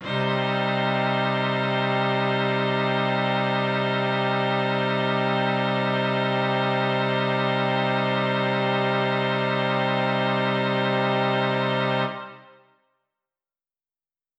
SO_KTron-Cello-Cmin.wav